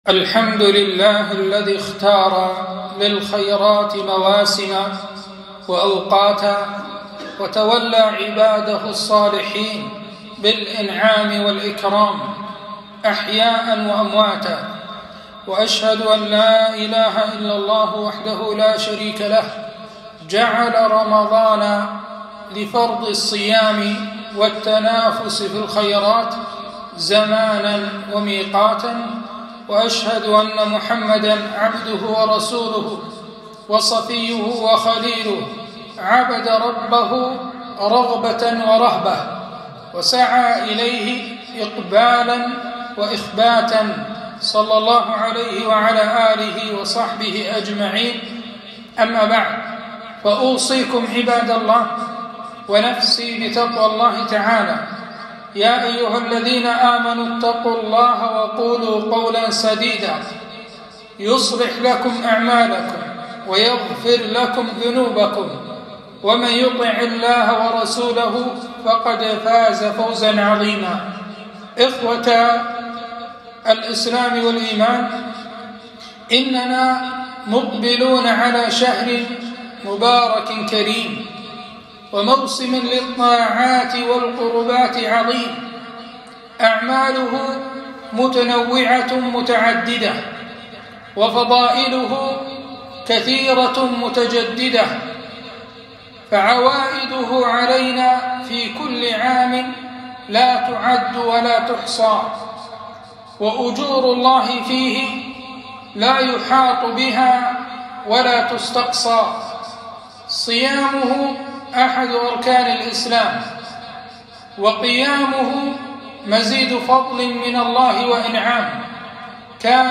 خطبة - تعرضوا لنفحات ربكم